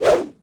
footswing1.ogg